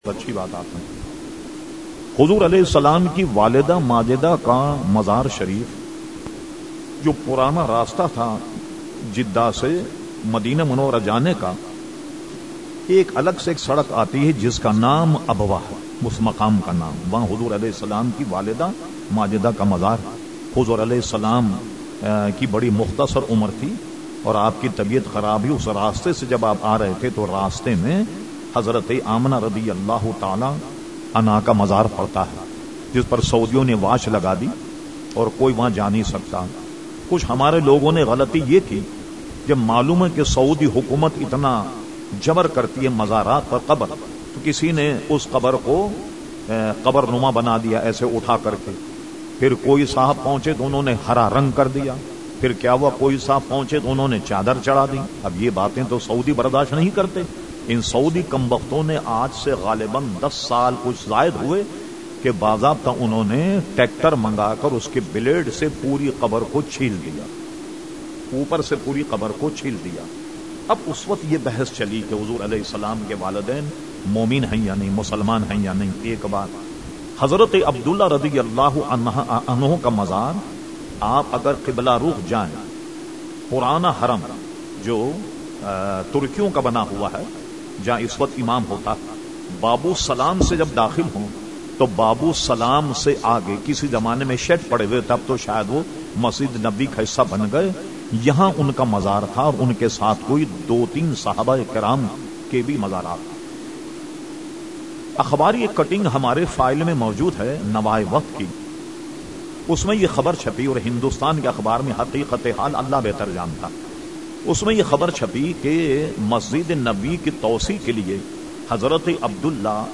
Q/A Program held on Sunday 17 October 2011 at Masjid Habib Karachi.